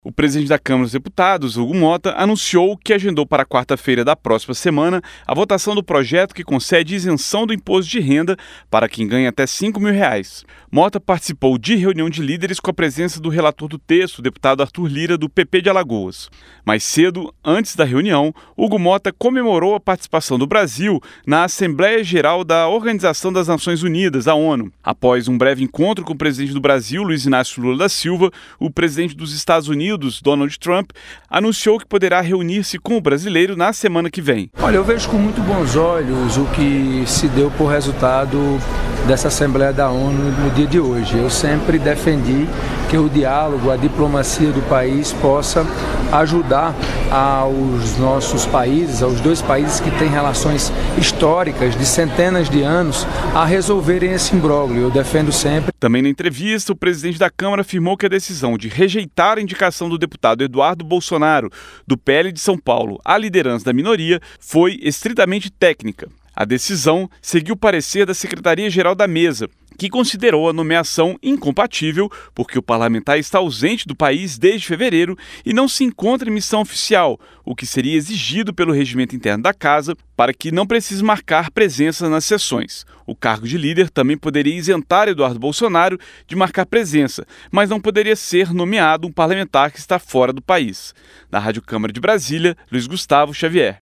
PRESIDENTE DA CÂMARA MARCA VOTAÇÃO DO PROJETO DO IMPOSTO DE RENDA. E NEGA REGISTRO DE LÍDER A EDUARDO BOLSONARO. SAIBA MAIS NA REPORTAGEM